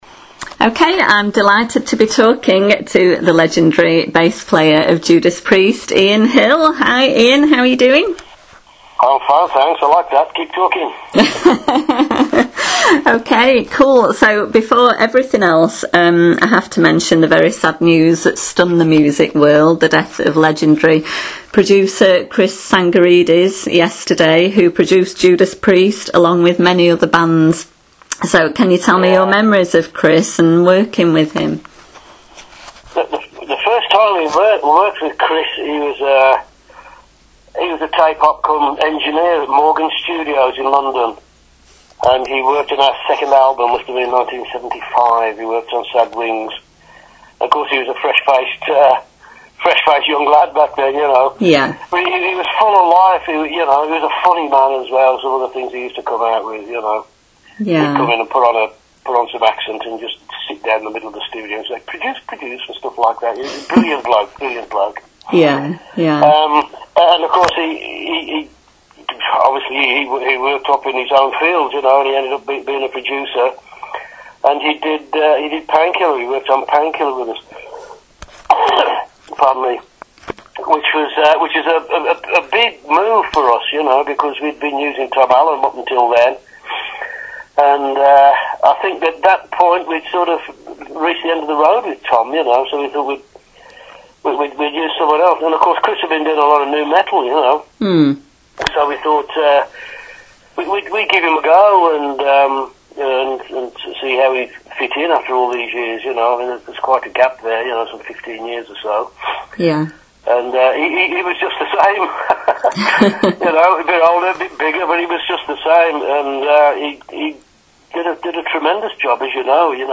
I was very fortunate to be able to speak to legendary JUDAS PRIEST bass player Ian Hill about the new release and as gain as much information as I could in the short time available! We also discussed the forthcoming tour, festivals and more.
interview-ian-hill.mp3